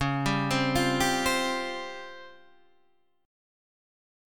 C# Major 7th Flat 5th